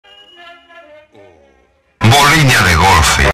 Play Bolinha De Golfe Estourado - SoundBoardGuy
Play, download and share bolinha de golfe estourado original sound button!!!!
bolinha-de-golfe-estourado.mp3